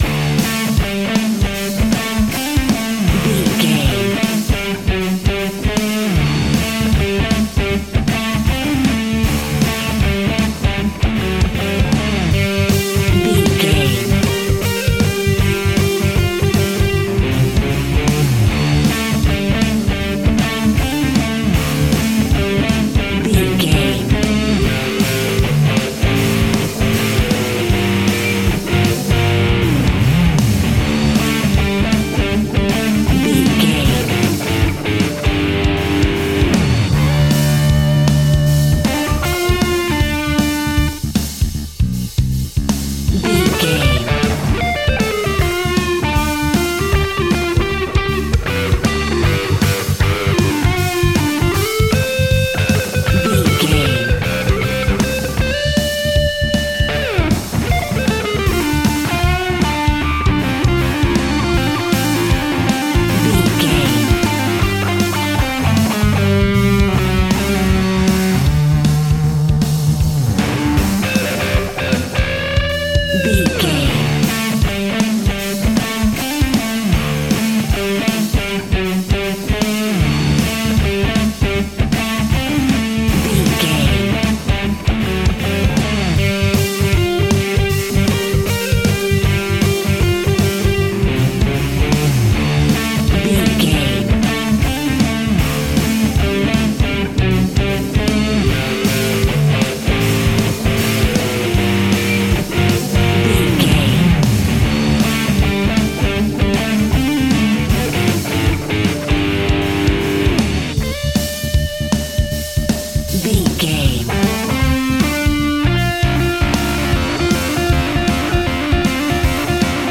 Aeolian/Minor
hard rock
blues rock
distortion
instrumentals
Rock Bass
Rock Drums
distorted guitars
hammond organ